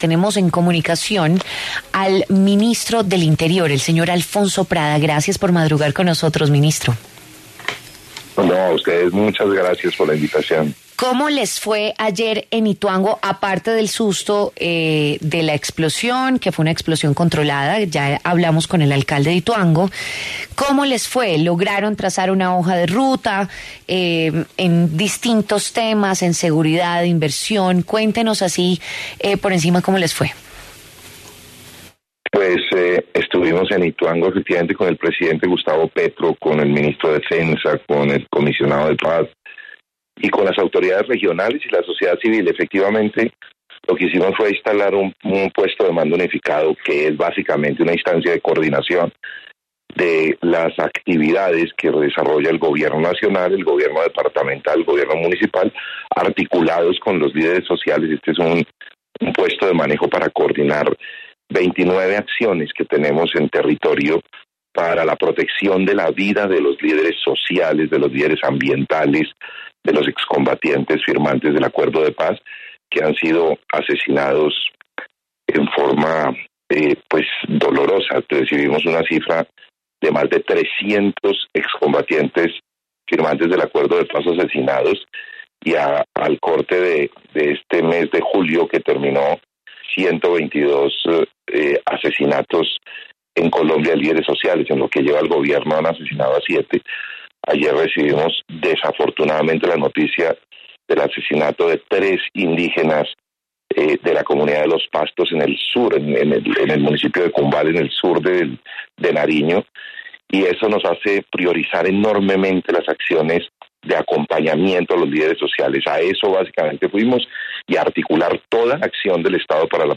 Alfonso Prada, el ministro del Interior, conversó en W Fin de Semana sobre los principales acuerdos a los que se llegó en la visita a Ituango, Antioquia.